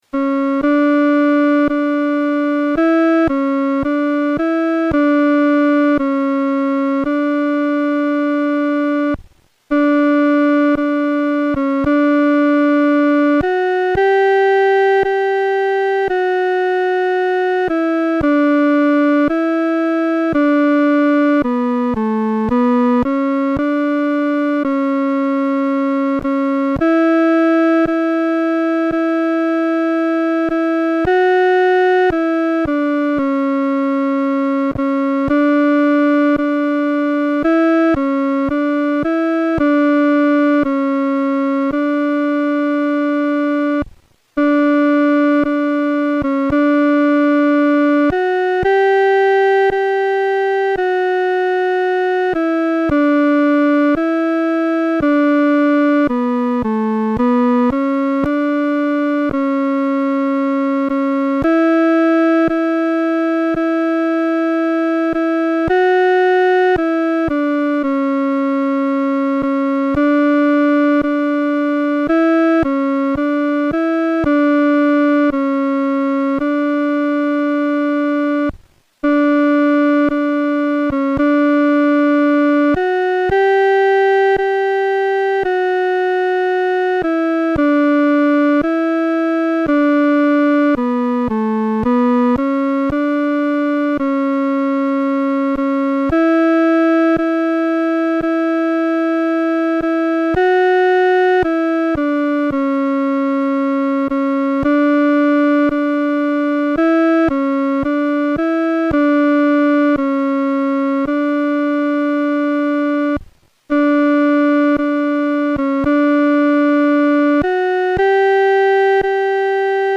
伴奏
女低
本首圣诗由石家庄圣诗班录制